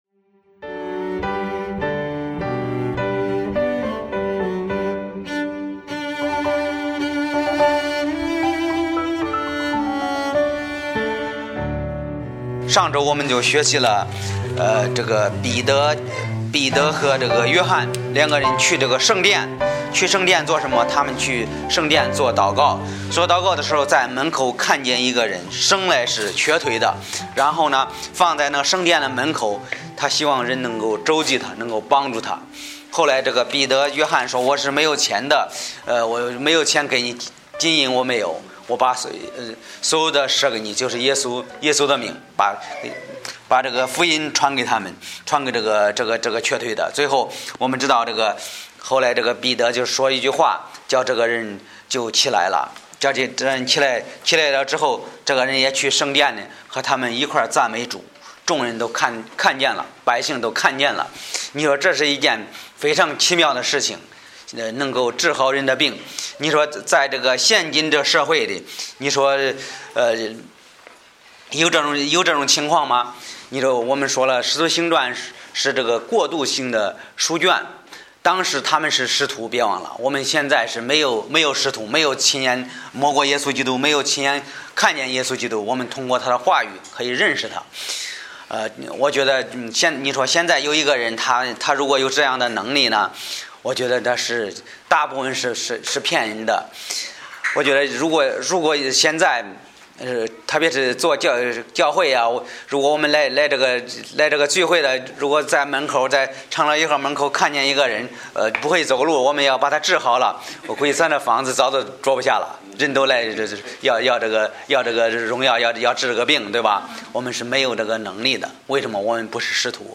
讲道者